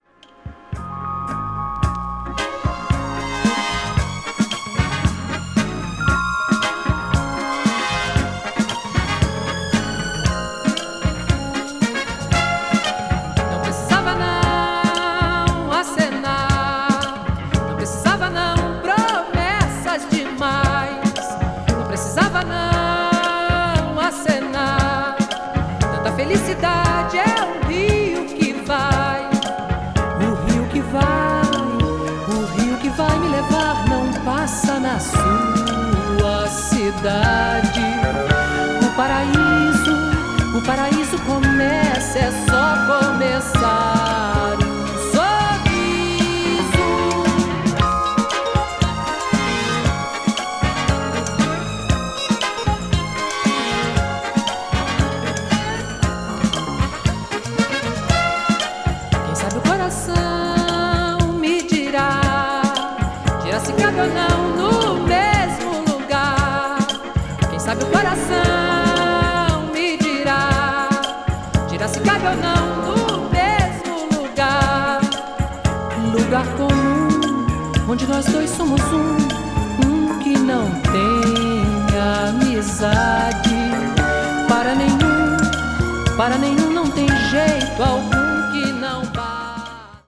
The true highlight, however, is B3—a Disco Tropicália gem.